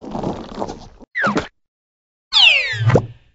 AV_teleport.ogg